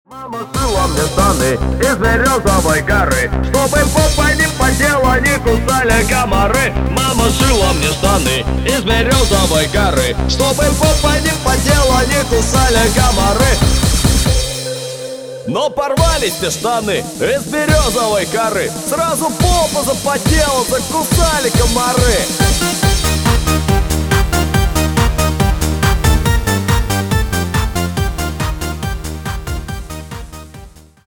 на русском клубные